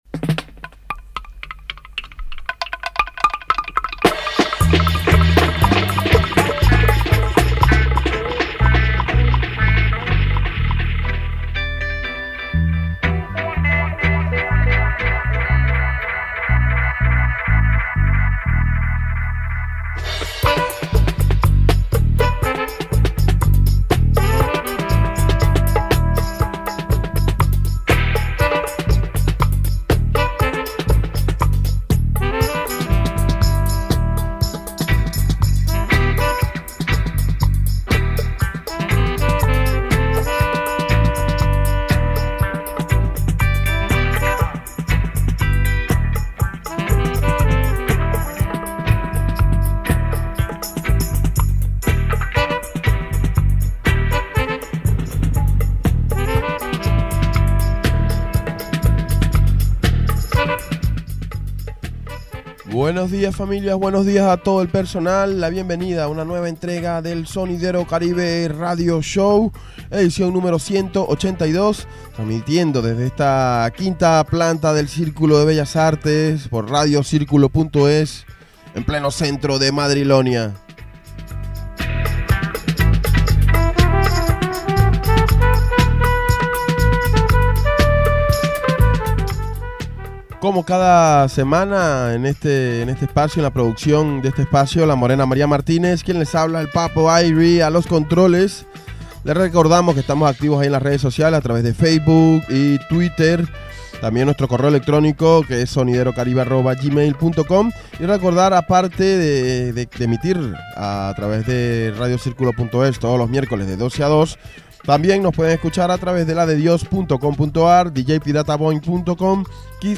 Nueva entrega del Sonidero Caribe Radio Show (#182)….con un 90% de la selección girando a 45 rpm…